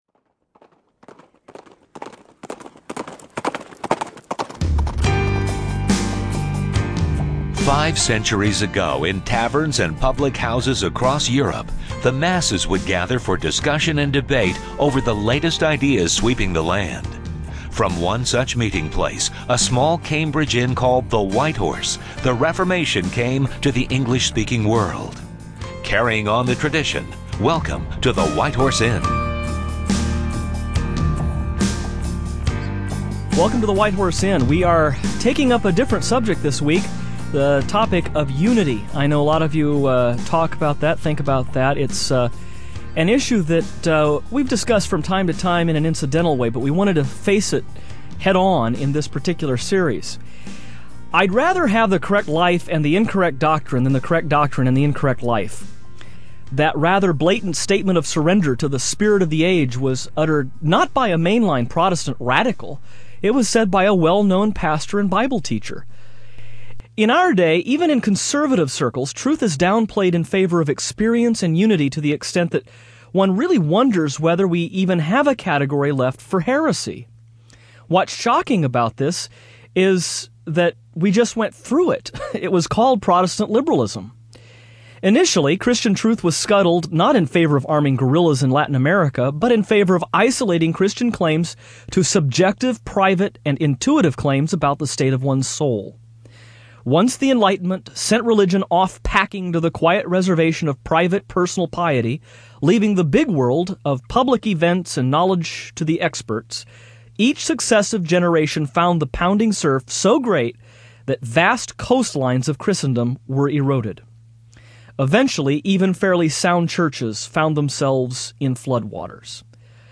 But, as the hosts explain on this program, the prospects for unity seem dim when Christians from across the continent seem to have forgotten that…